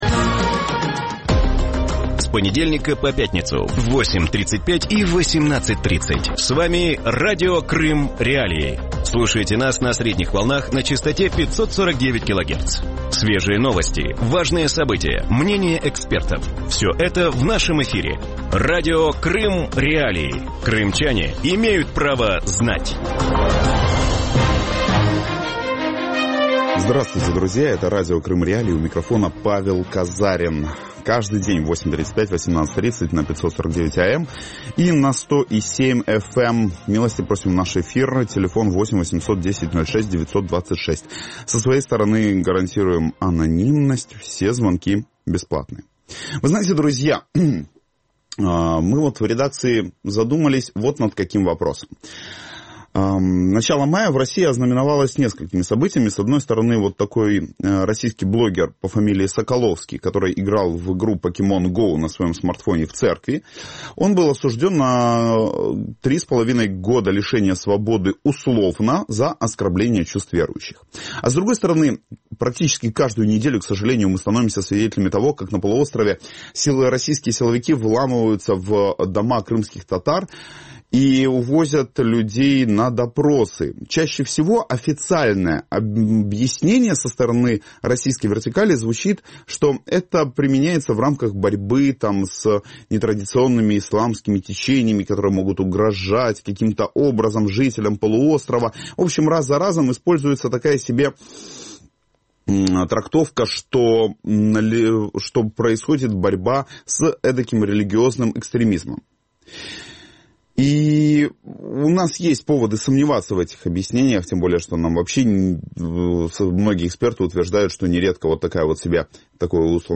Вранці в ефірі Радіо Крим Реалії говорять про переслідування кримських мусульман на півострові за релігійною ознакою.